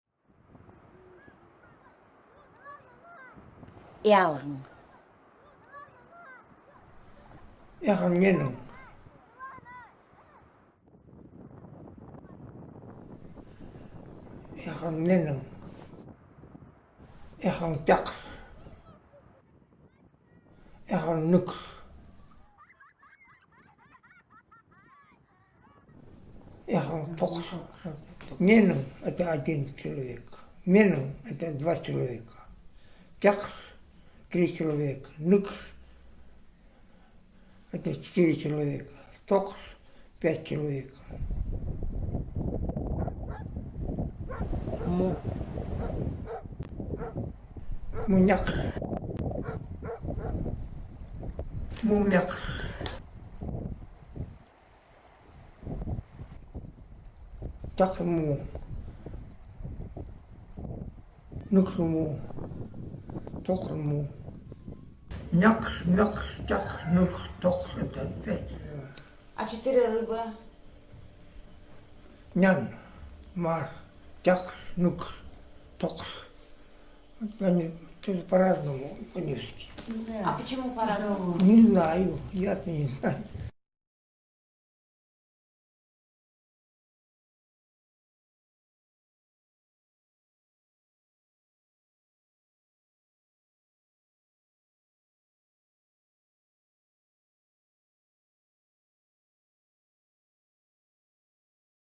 считает лодки, детей и рыб. Для этого используются разные системы числительных.